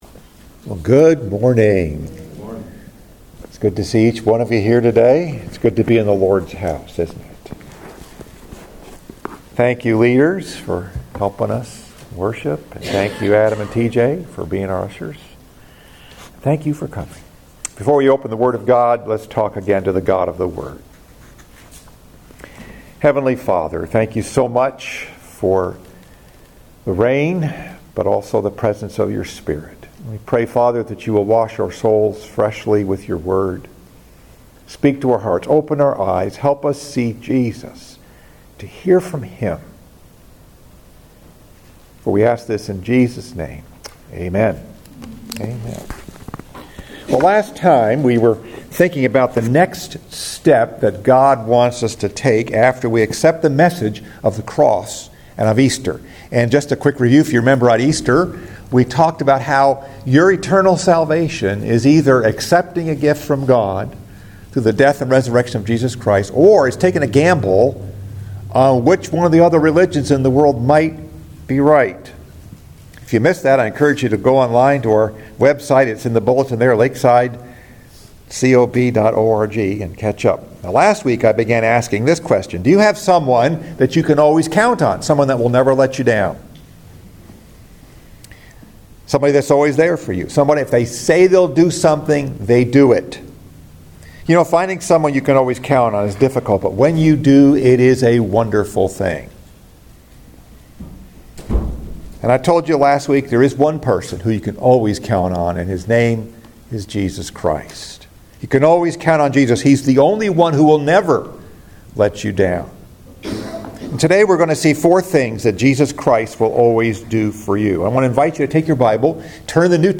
Message: “The Next Step” Part 2 Scripture: John 21:1-14 Second Sunday after Easter